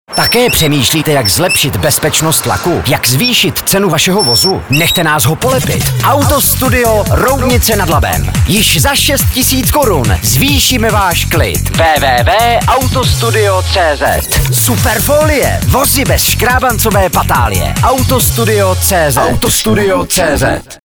Od 3.11. do 7.11 byly odvysílány naše dva reklamní spoty na Evropě 2 Ústí, kde Vás informujeme, že do konce Listopadu je akce, kdy polepíme Váš vůz se slevou, respektive polep jednoho dílu zdarma.